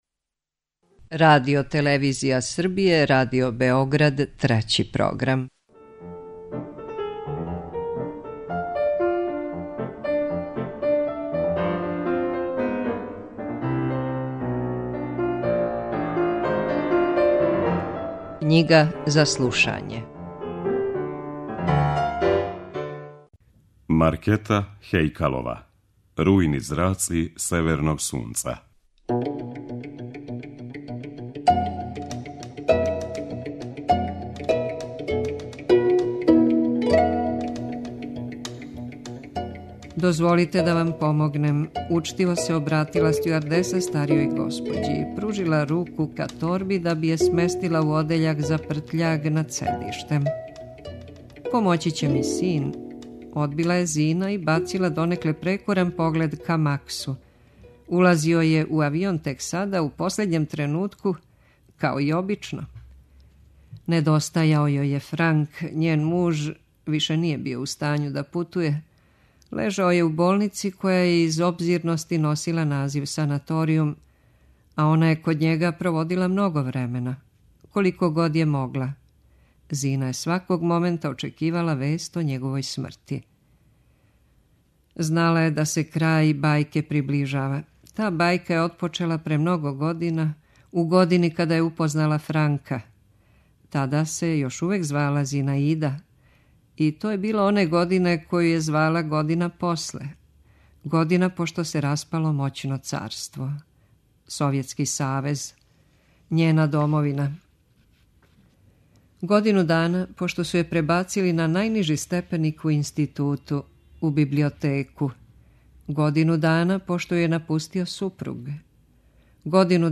преузми : 8.92 MB Књига за слушање Autor: Трећи програм Циклус „Књига за слушање” на програму је сваког дана, од 23.45 сати.